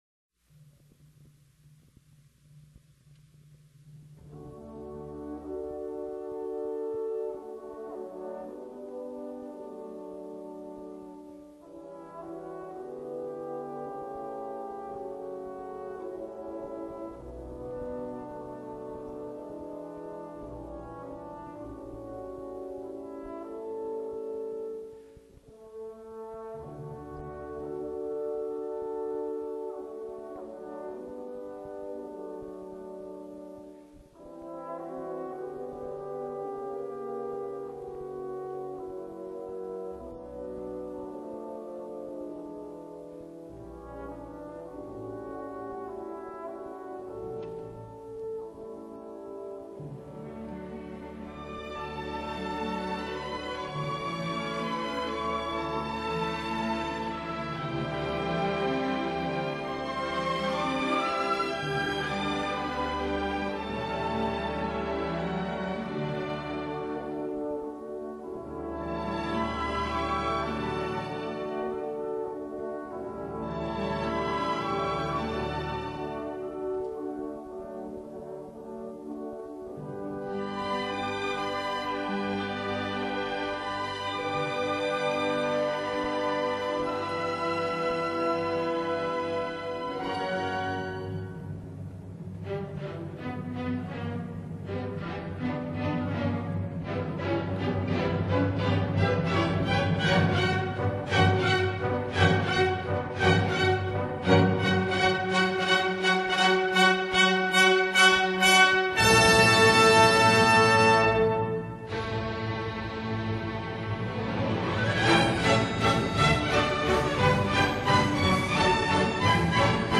【所属类别】音乐 古典